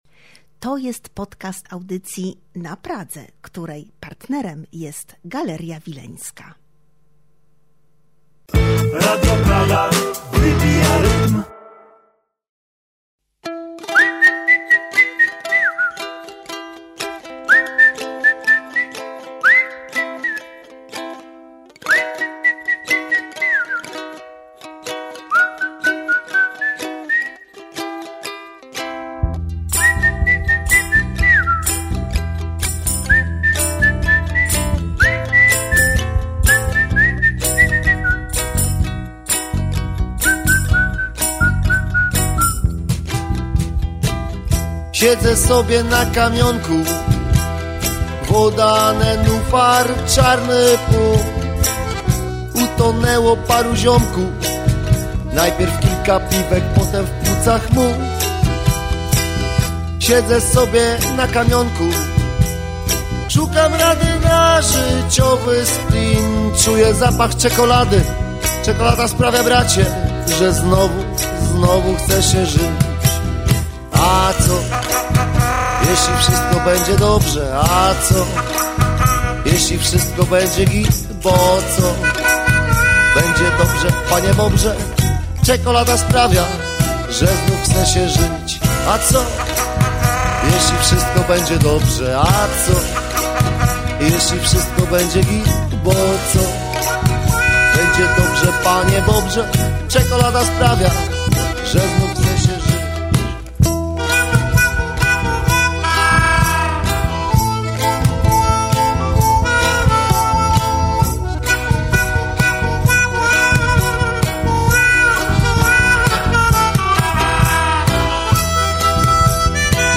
Rozmawiamy o muzyce, Odessie, koncertach i debiucie fonograficznym. Jest też ” wykon” na żywo w studio.